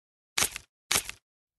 6. Щелканье клешней краба